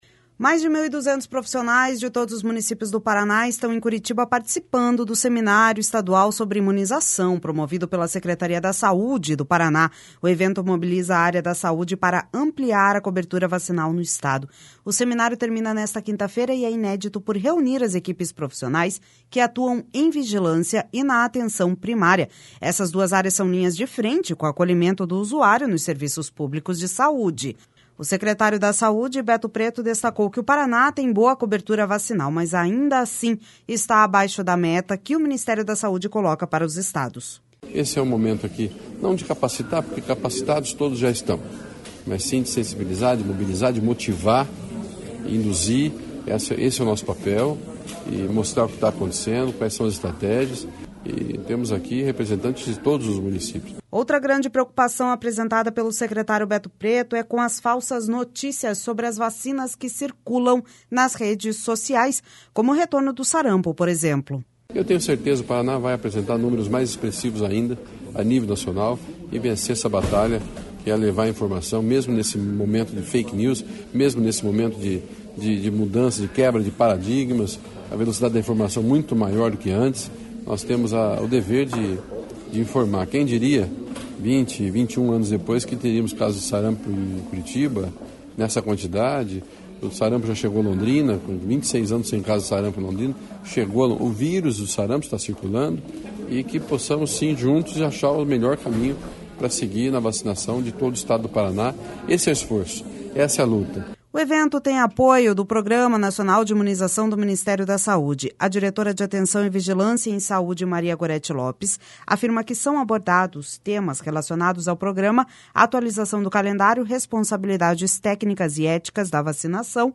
O secretário da Saúde, Beto Preto, destacou o Paraná tem boa cobertura vacinal, mas ainda assim está abaixo da meta que o Ministério da Saúde coloca para os estados.// SONORA BETO PRETO//Outra grande preocupação apresentada pelo secretário Beto Preto é com as falsas notícias sobre as vacinas que circulam nas redes sociais, como o retorno do sarampo, por exemplo.// SONORA BETO PRETO//O evento tem apoio do Programa Nacional de Imunização do Ministério da Saúde.